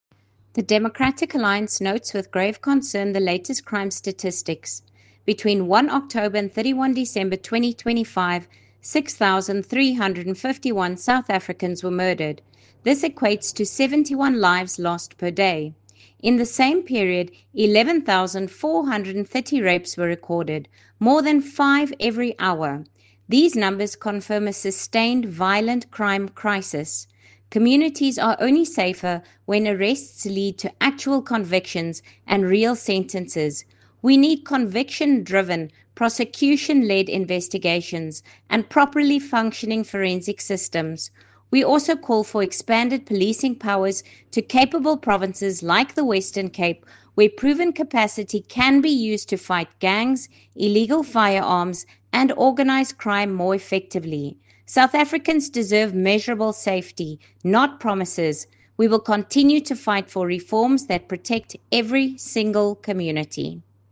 Issued by Lisa Schickerling MP – DA Spokesperson on Police
Attention broadcasters: Please find audio by Lisa Schickerling MP in